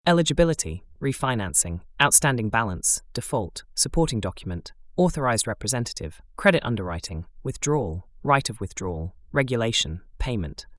Entraînez-vous à prononcer ces mots en anglais. Cliquez sur les icônes fille et garçon pour écouter la prononciation.